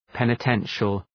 Προφορά
{,penə’tenʃəl}